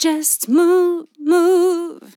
Just move move Vocal Sample
Categories: Vocals Tags: DISCO VIBES, dry, english, female, Just, LYRICS, move, sample
POLI-LYRICS-Fills-120bpm-Fm-19.wav